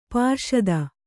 ♪ pārṣada